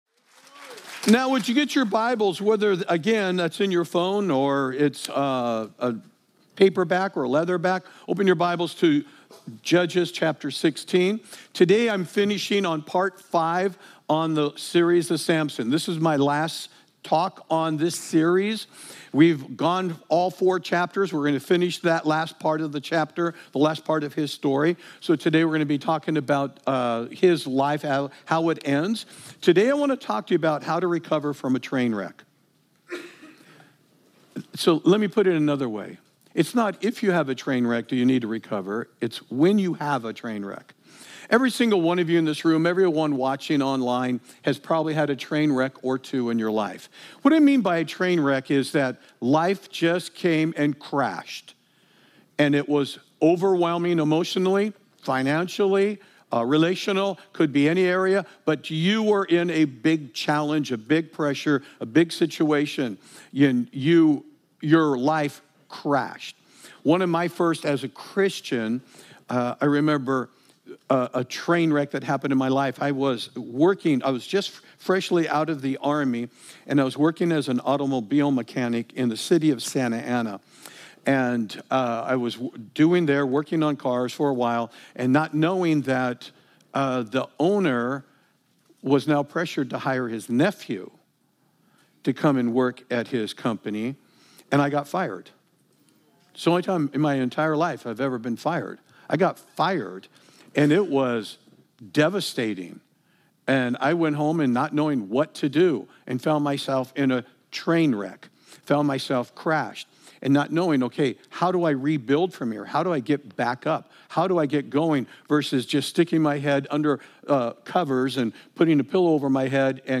Download Download Samson Current Sermon How To Recover From A Train Wreck